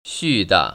수다 떨다 발음 : [ xù‧da ]